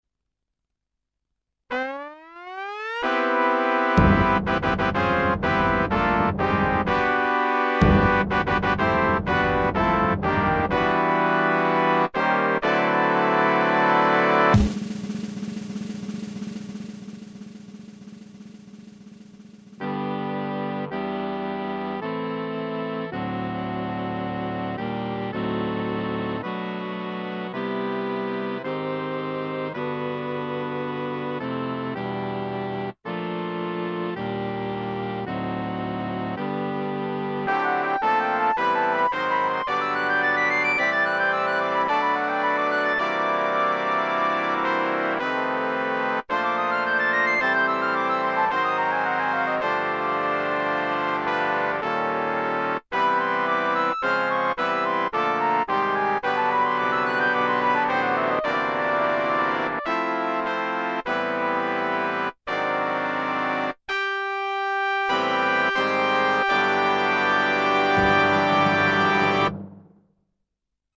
Includes Strings and Electric Piano
This arrangement for Big Band is just an exercise in ‘updating’ the traditional harmonies and chord progressions.